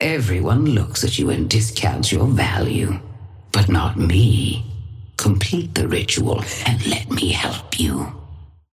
Sapphire Flame voice line - Everyone looks at you and discounts your value, but not me.
Patron_female_ally_viscous_start_03.mp3